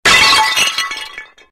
Tarelka.ogg